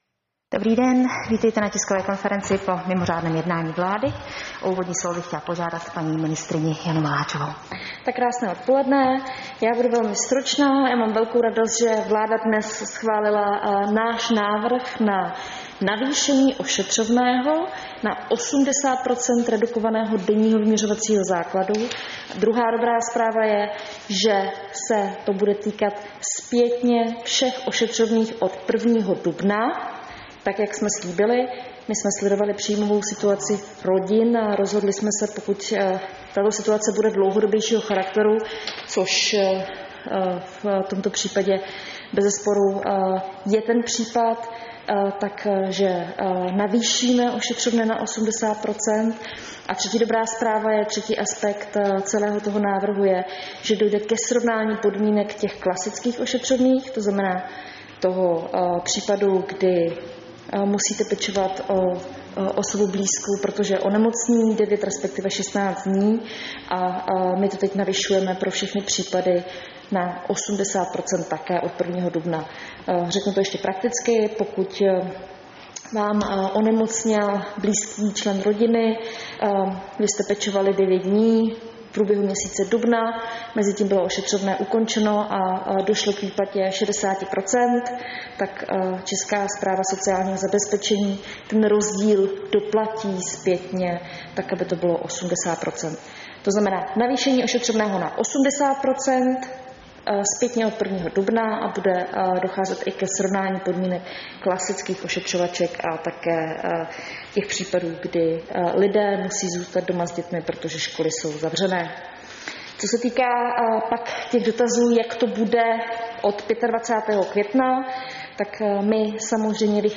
Tisková konference po mimořádném jednání vlády, 17. dubna 2020